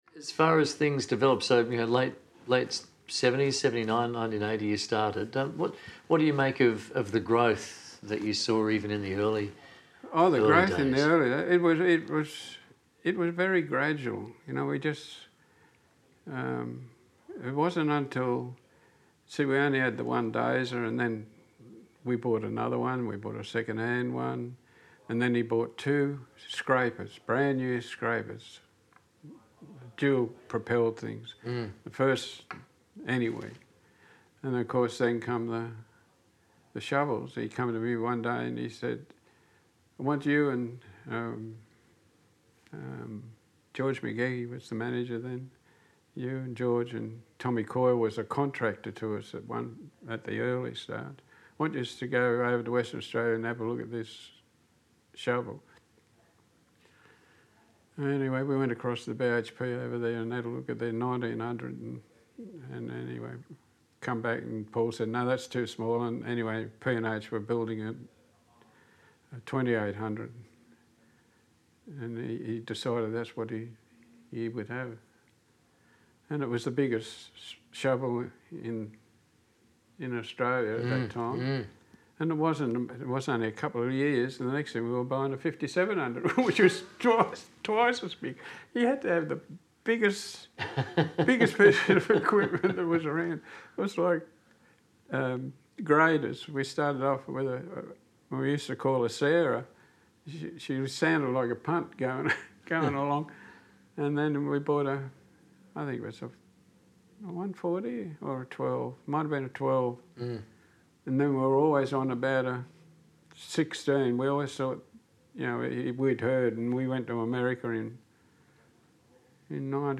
a series of interviews